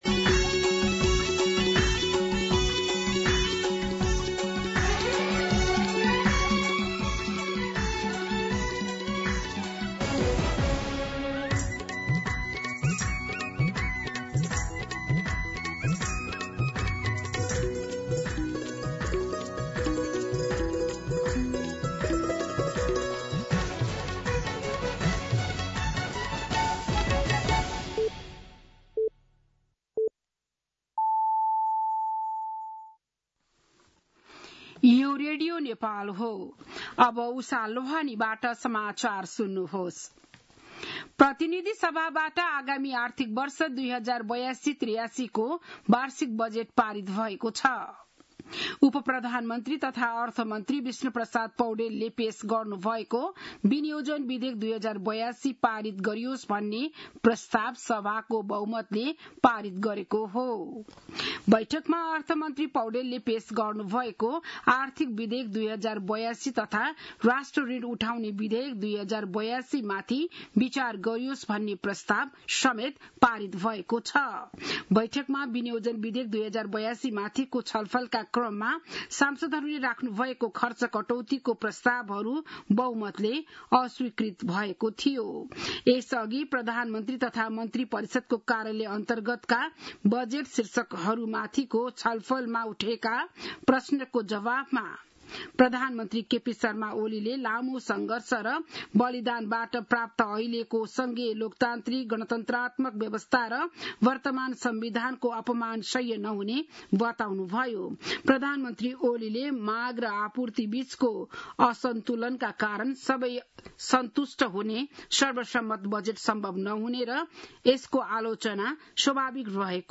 बिहान ११ बजेको नेपाली समाचार : ११ असार , २०८२